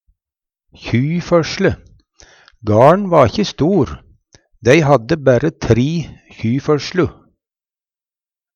føsle - Numedalsmål (en-US)